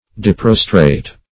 Search Result for " deprostrate" : The Collaborative International Dictionary of English v.0.48: Deprostrate \De*pros"trate\, a. Fully prostrate; humble; low; rude.
deprostrate.mp3